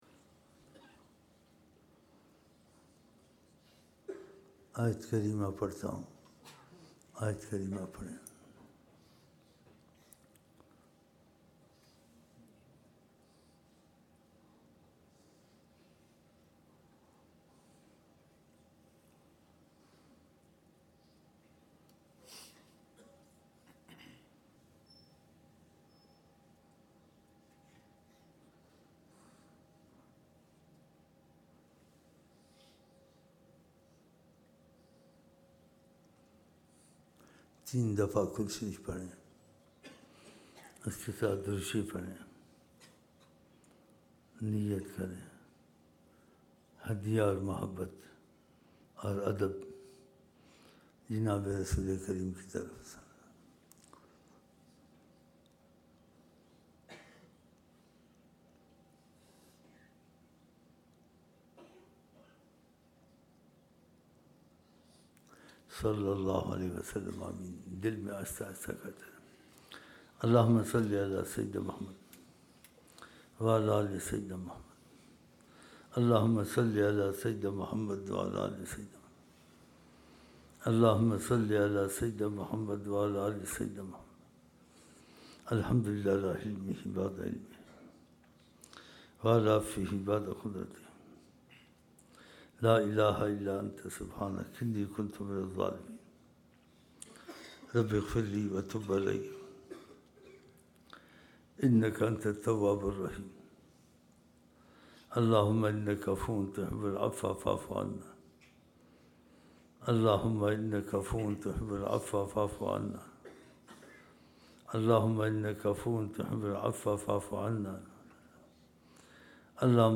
07 January 2007 Sunday Esha Mehfil (16 Dhul Hijjah 1427 AH)
Naatiya Ashaar Naat